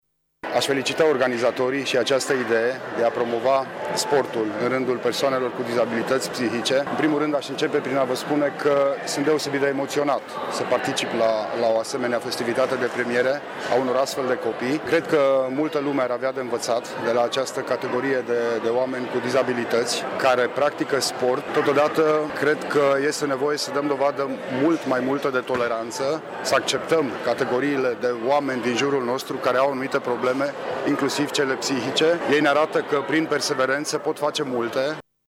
Prefectul judeţului Mureş, Lucian Goga, a spus că s-a simţit emoţionat să participle la această premiere deoarece aceşti copii ne învaţă că dacă eşti perseverant poţi face orice îţi propui: